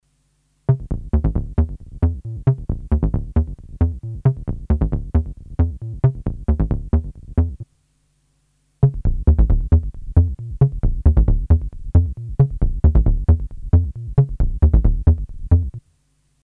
Bottom end
mp3 file played on a decent sub shows the difference the bottom end mod makes.
Both 303s play the same pattern, tuned down to minimum pitch.
The extra bass on the modded 303 can be rolled off by a high pass filter on a mixer channel if required, but why would you want to ? ;-)